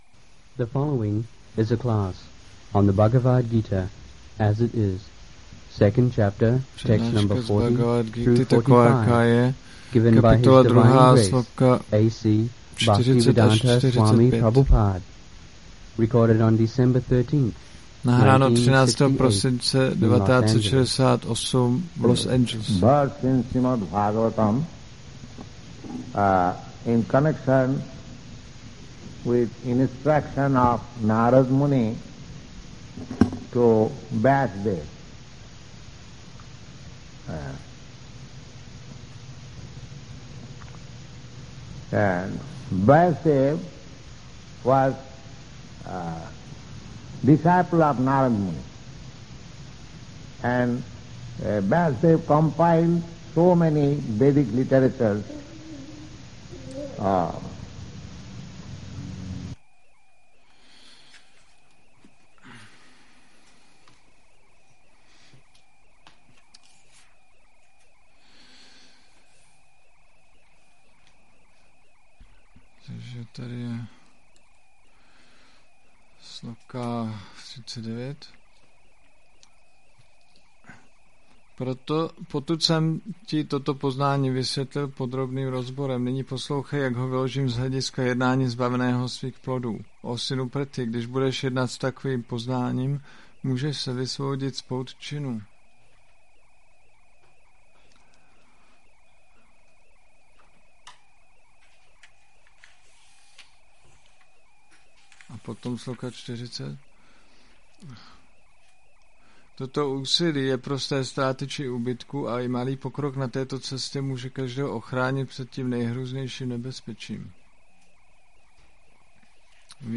1968-12-13-ACPP Šríla Prabhupáda – Přednáška BG-2.40-45 Los Angeles